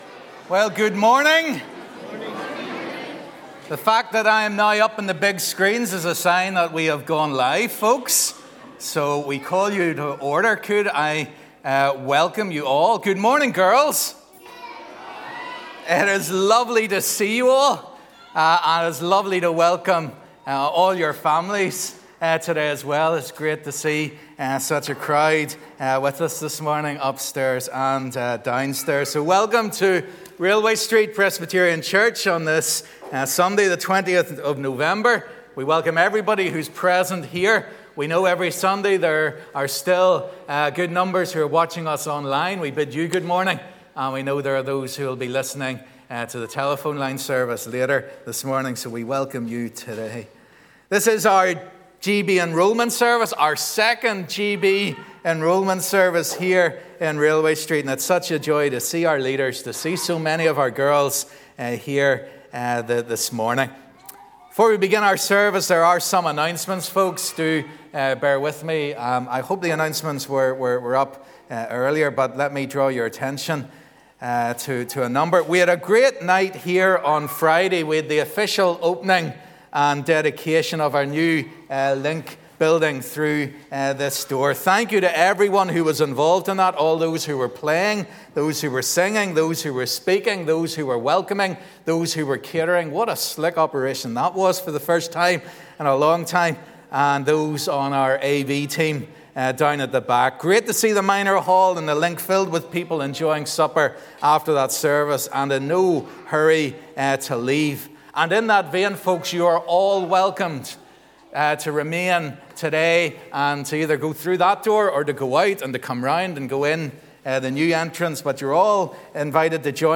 Railway Street Girl's Brigade: Service Of Enrolment
Welcome to the annual enrolment service of the 375th NI Railway Street Presbyterian Girls' Brigade.